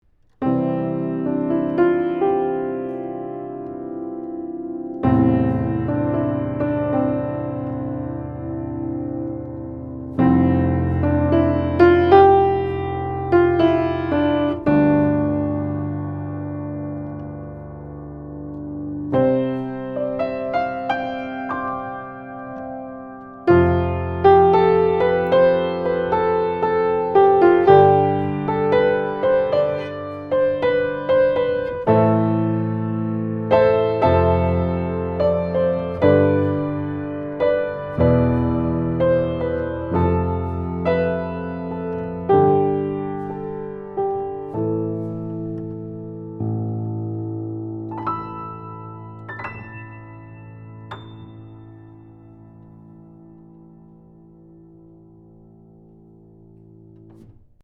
Here are several quick, 1-take MP3 sound files to give you an idea of what to expect. These MP3 files have no compression, EQ or reverb -- just straight signal, tracked with this Rode NT1-A mic into a TAB-Funkenwerk V78M preamp using a Sony PCM DI flash recorder.
SHAFER & SONS BABY GRAND
ShaferNT1aV78D1Piano1.mp3